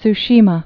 (ts-shēmə, tsshē-mä)